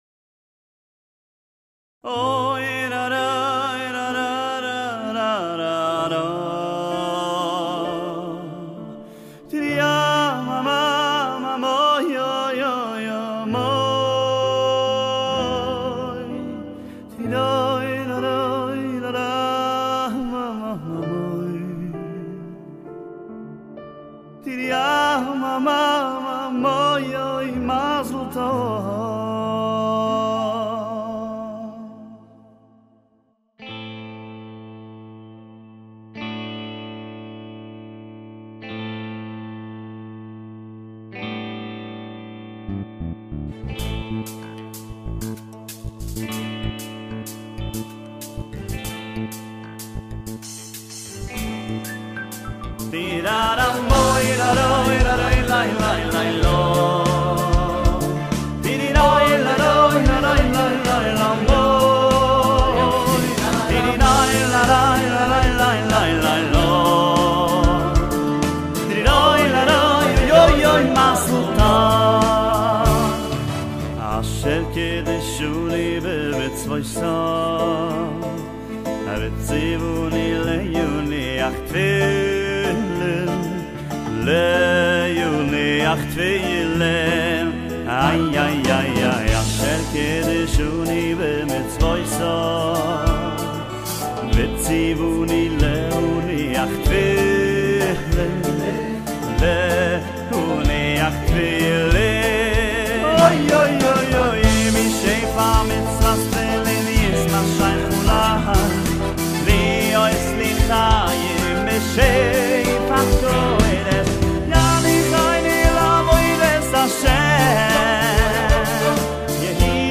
במהלך השמחה בהשתתפות מיטב זמרי ארה"ב